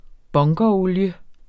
Udtale [ ˈbɔŋgʌˌoljə ]